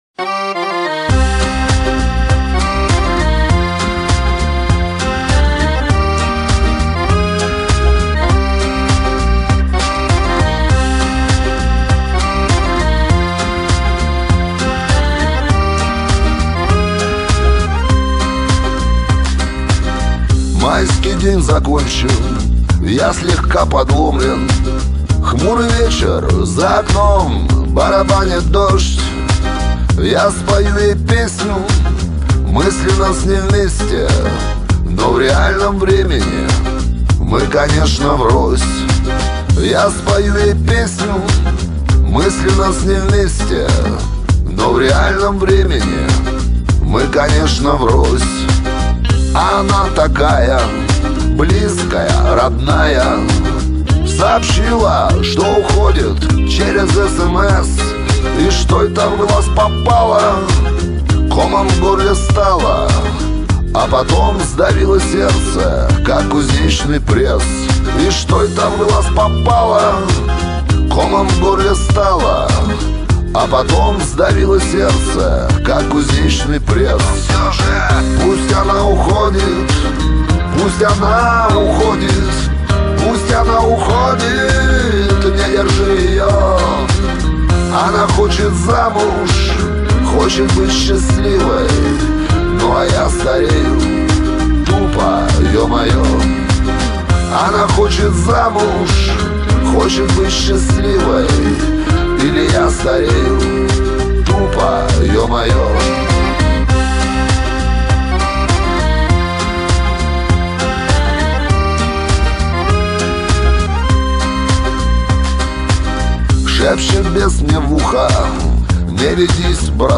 Главная » Файлы » Шансон 2016